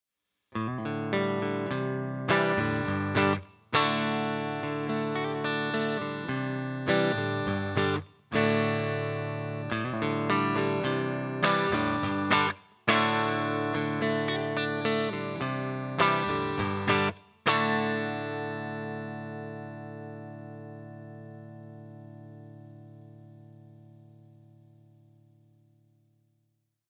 65R (1965 Tele type rhythm) alone